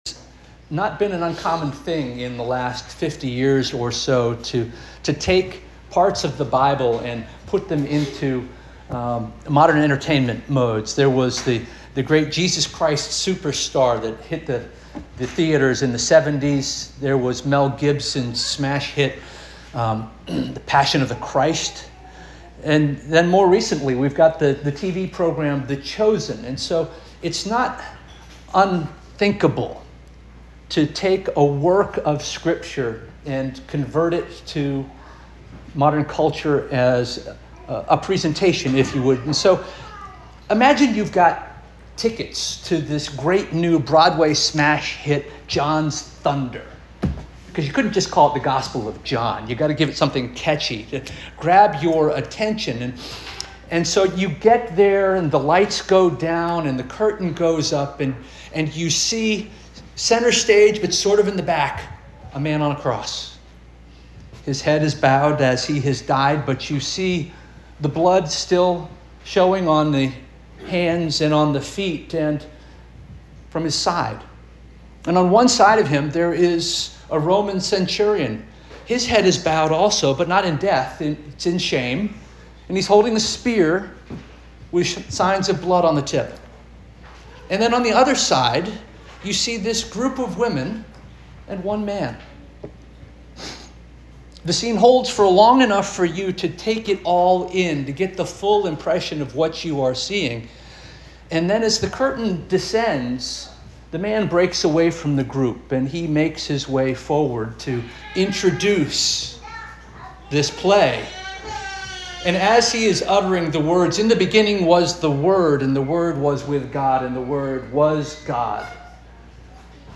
July 27 2025 Sermon